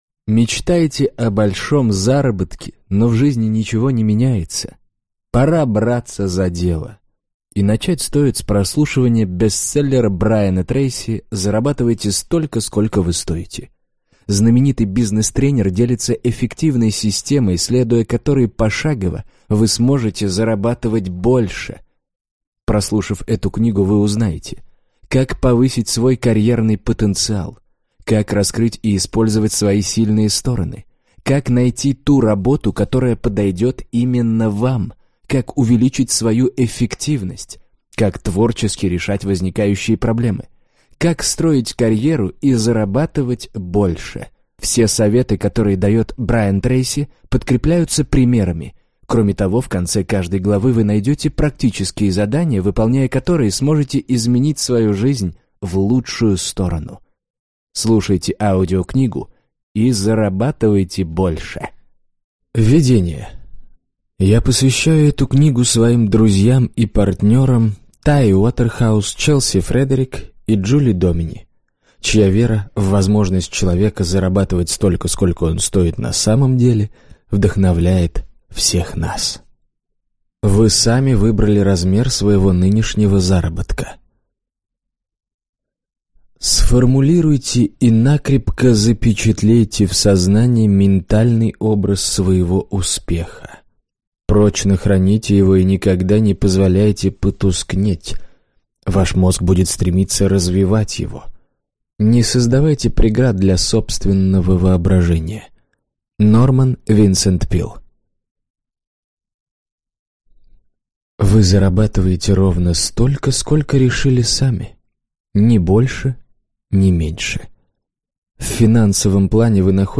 Аудиокнига Зарабатывайте столько, сколько вы стоите | Библиотека аудиокниг